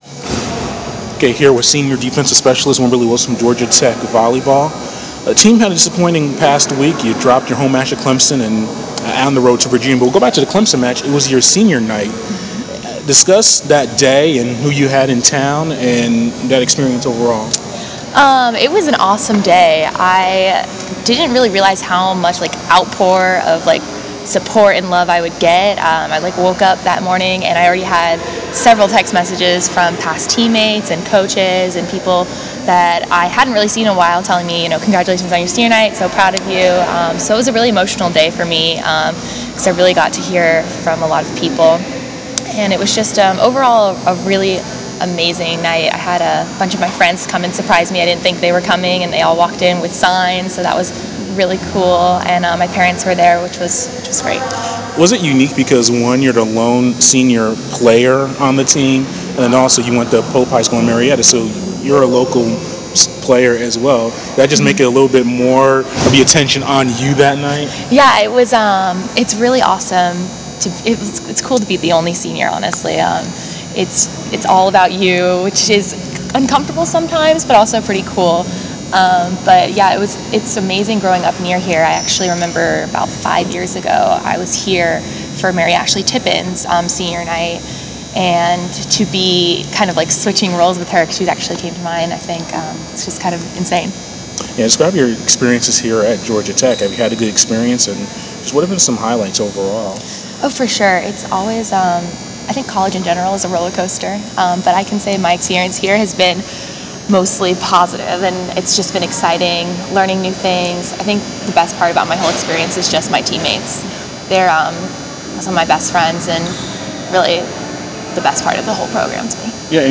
interview
before her team’s practice on Nov. 17